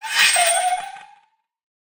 Minecraft Version Minecraft Version latest Latest Release | Latest Snapshot latest / assets / minecraft / sounds / mob / allay / idle_with_item3.ogg Compare With Compare With Latest Release | Latest Snapshot